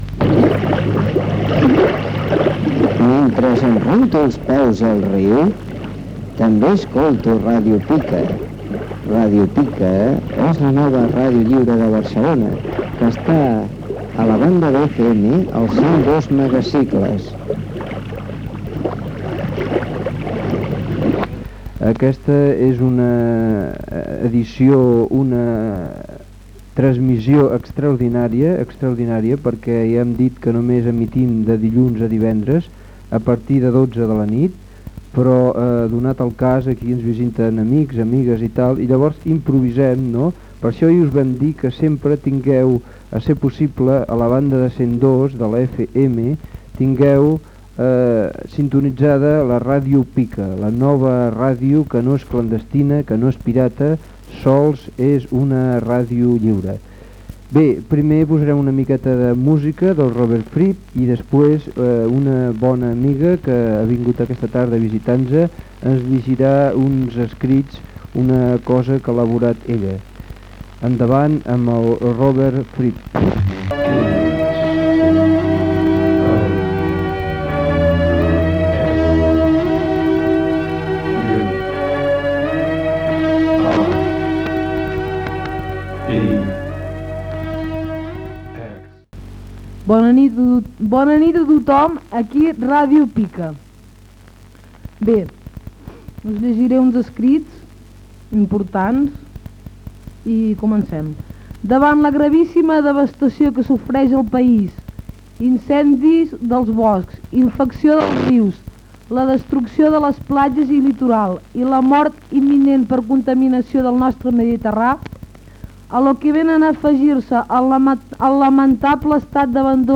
Indicatiu i lectura d'un escrit de denúncia social.